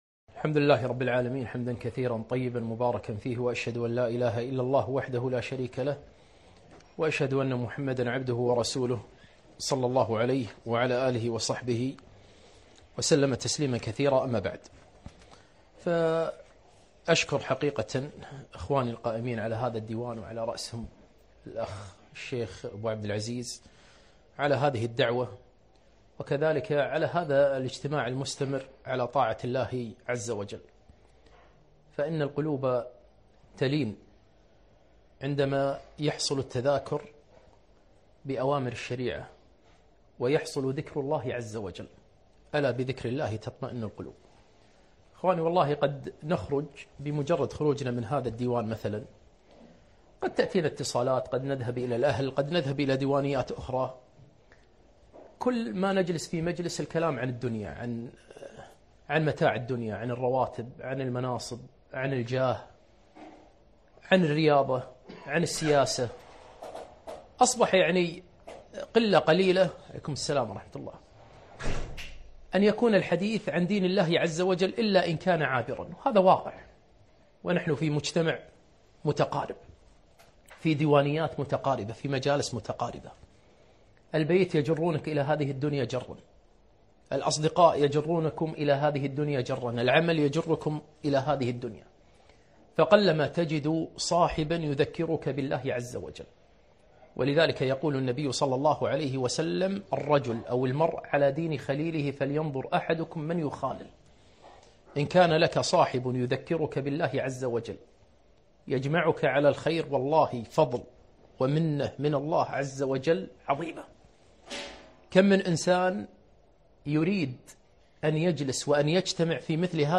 محاضرة - حال الأسر في رمضان